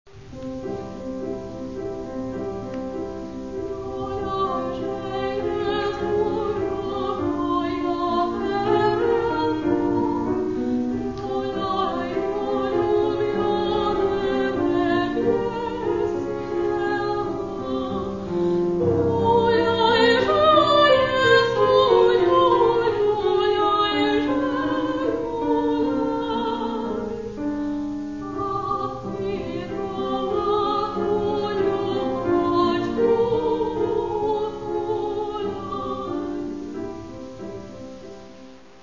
Каталог -> Класична -> Нео, модерн, авангард
для голосу і фортепіано